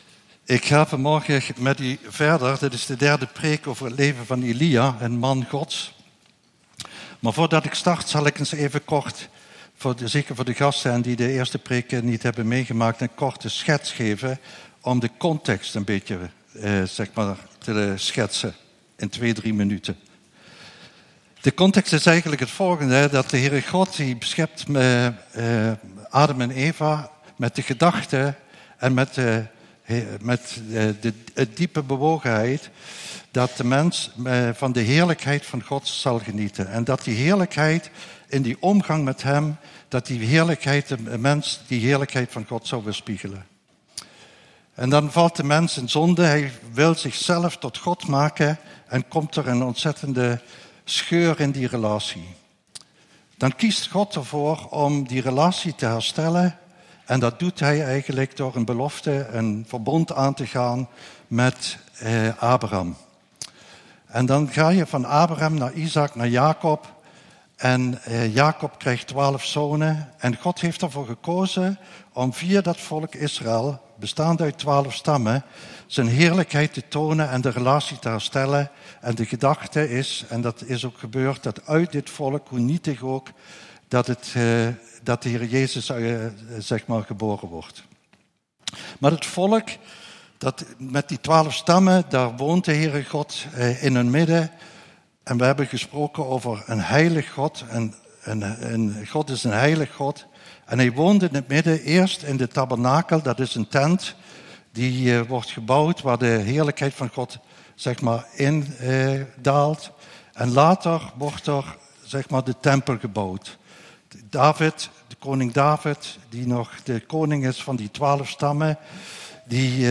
een man die de moed had tegen de stroom in te gaan Passage: 1 Koningen 18:21-40 Dienstsoort: Eredienst